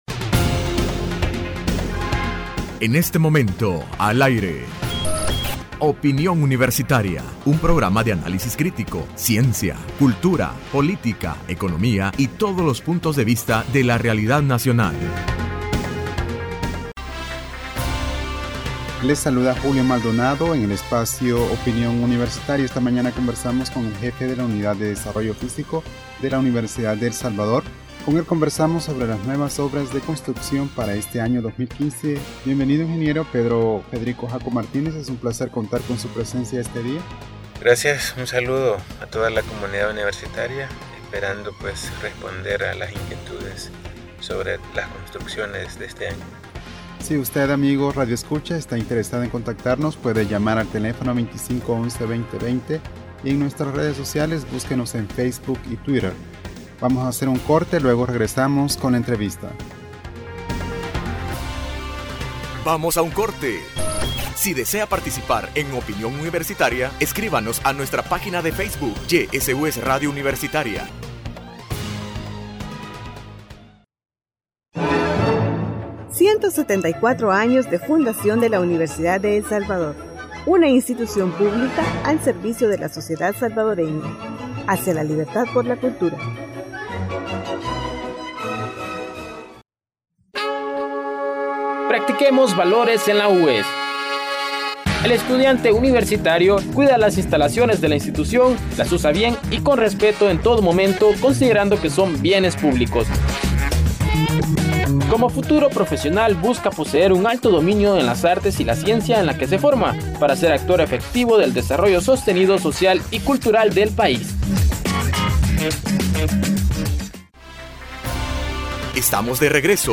Entrevista Opinión Universitaria (4 marzo 2015): Trabajos de nueva infraestructura en la Universidad de El Salvador